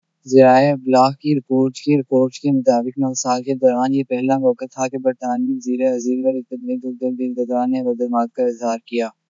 deepfake_detection_dataset_urdu / Spoofed_Tacotron /Speaker_02 /258.wav